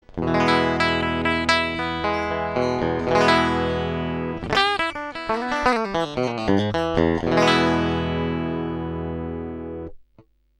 가는 현은 레인지가 넓게 엣지가 나오고, 굵은 현은 어택감이나 파워감이 있게 느껴집니다.